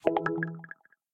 match-confirm.ogg